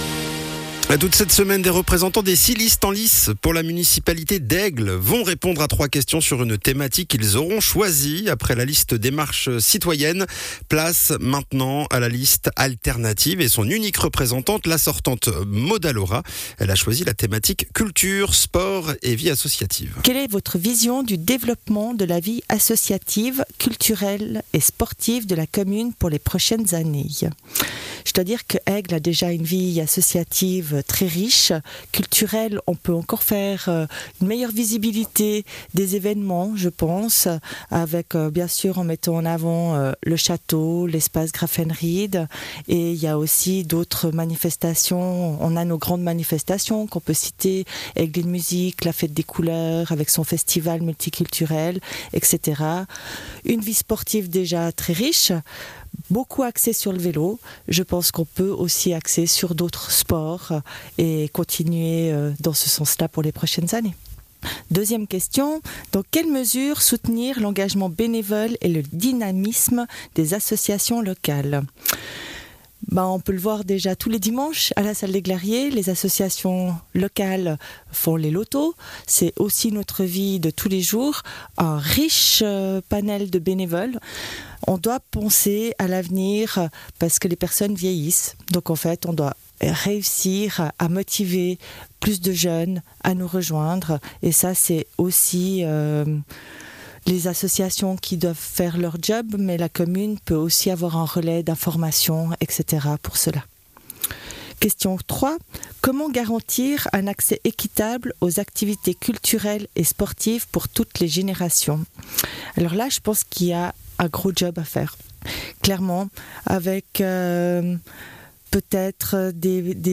Intervenant(e) : Maude Allora, municipale sortante et candidate sur la liste Alternatives